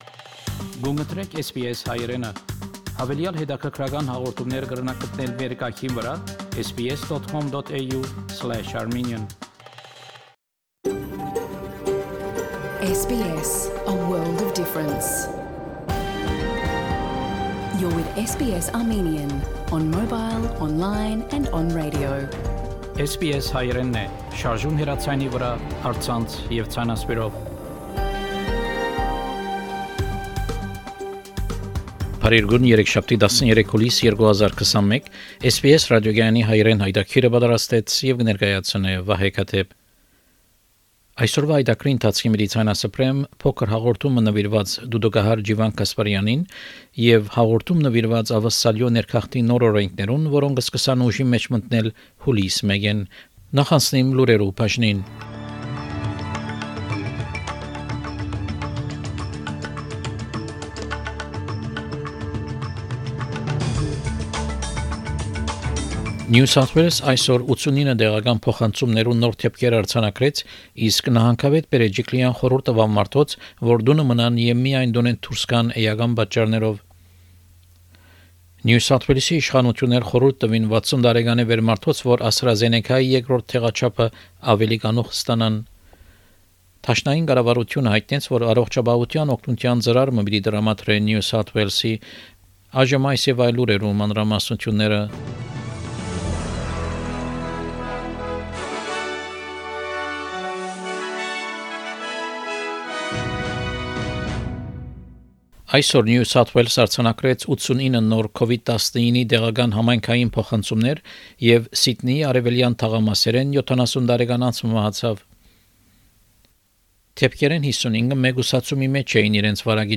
SBS Armenian news bulletin – 13 July 2021
SBS Armenian news bulletin from 13 July 2021 program.